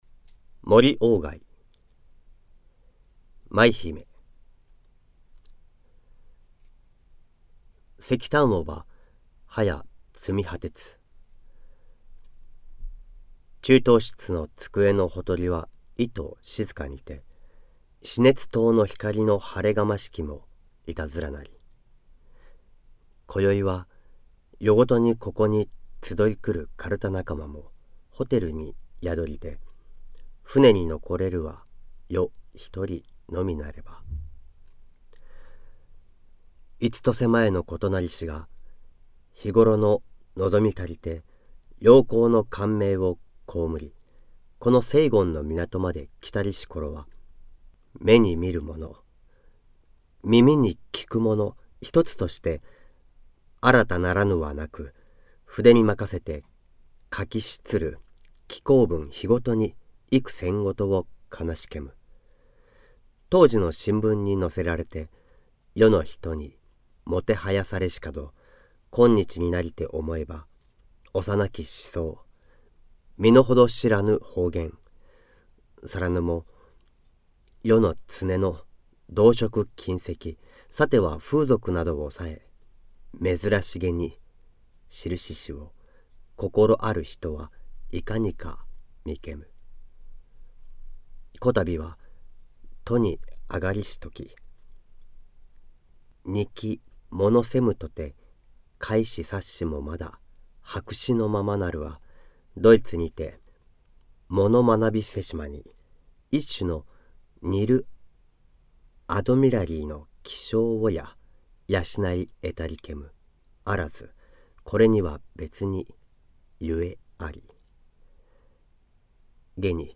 朗読本